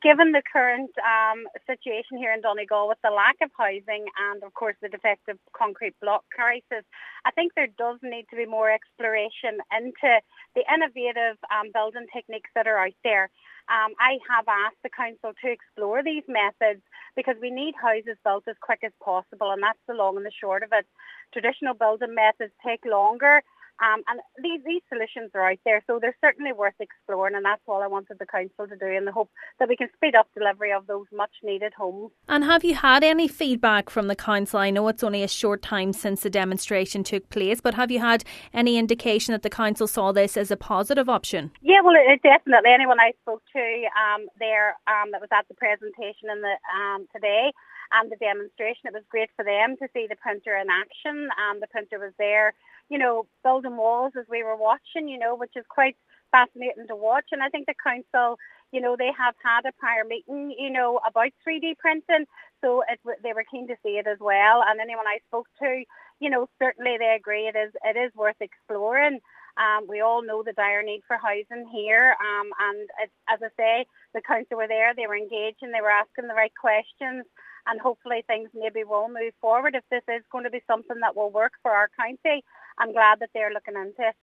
She says while the 3D printer may not be the answer, it has the potential to be part of the solution: